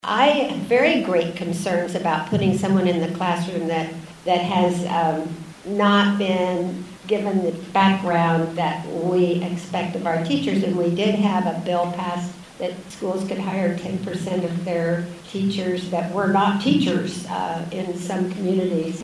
MANHATTAN — Hurdles to Medicaid expansion were a major highlight of Saturday’s legislative coffee held at the Sunset Zoo Nature Exploration Place.